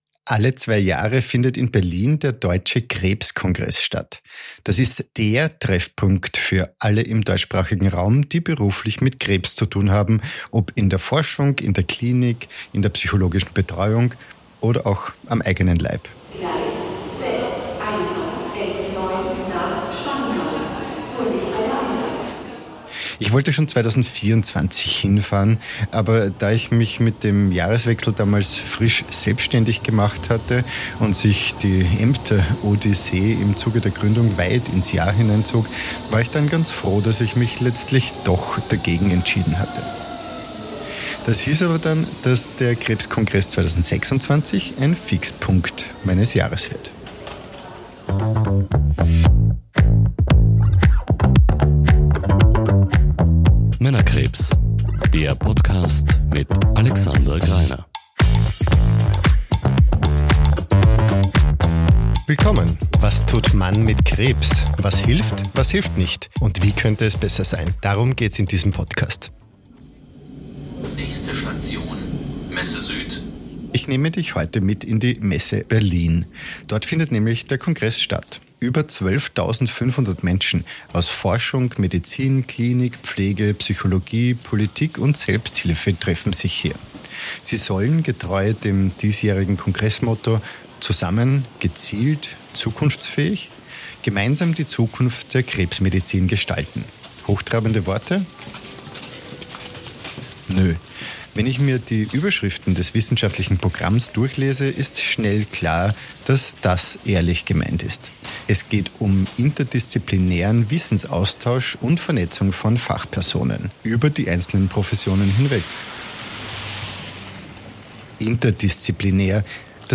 Über 12.500 Fachleute treffen sich beim DKK in Berlin. Woran wird derzeit geforscht, was verändert die Behandlung, und was bedeutet das für Menschen mit Krebs? Eine Reportage.